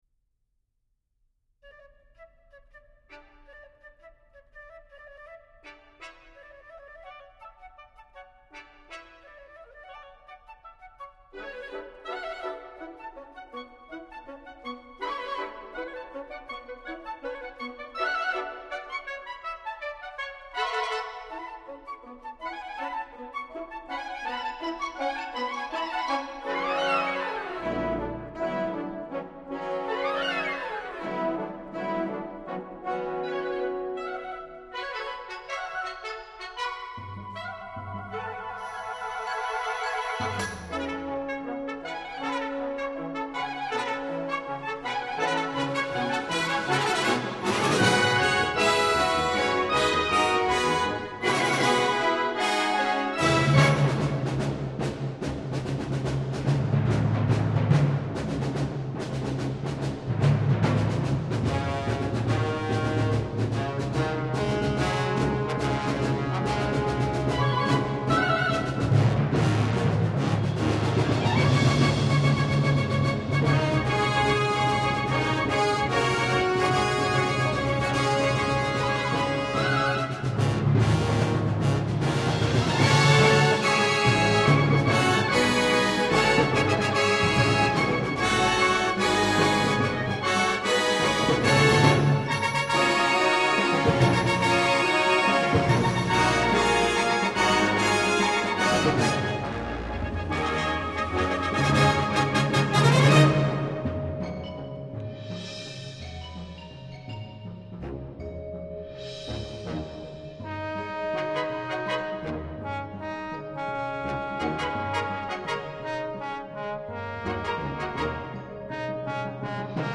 Genre: Band
Bb Trumpet 1, 2, 3, 4
Timpani (and tambourine)